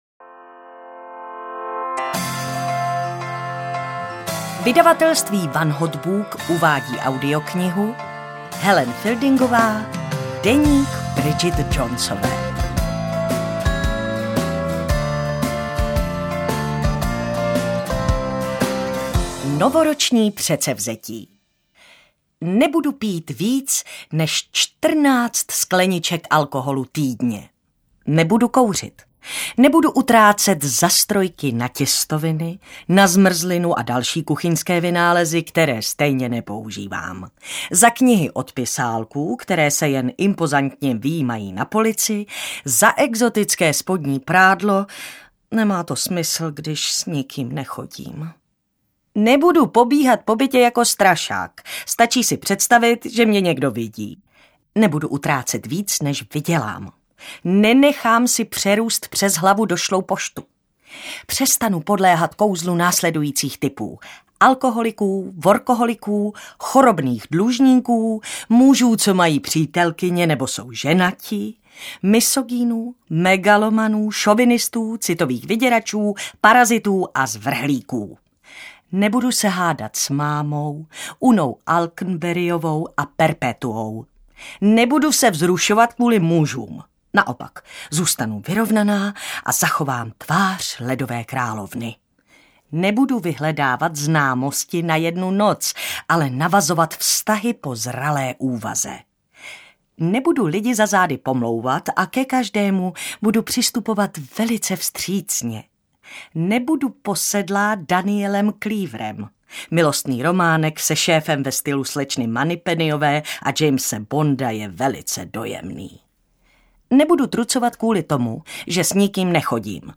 Interpret:  Martina Hudečková
AudioKniha ke stažení, 23 x mp3, délka 8 hod. 35 min., velikost 496,0 MB, česky